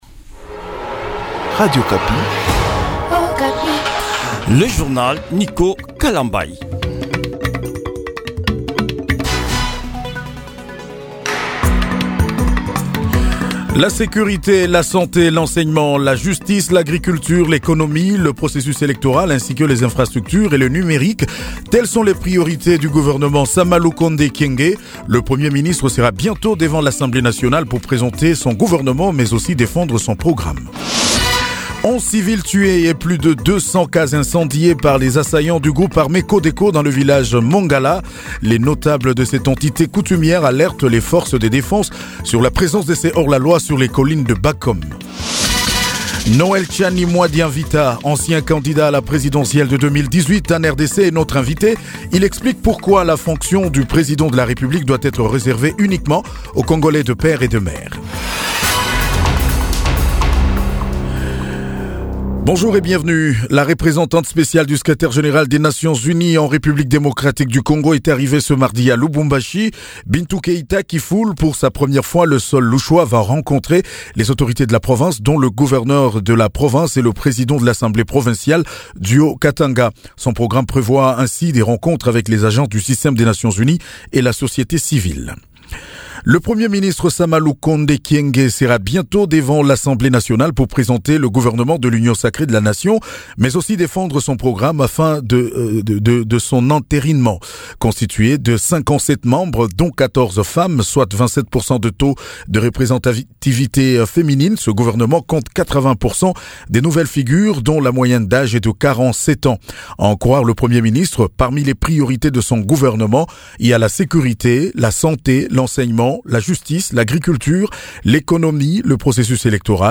JOURNAL MIDI DU MARDI 13 AVRIL 2021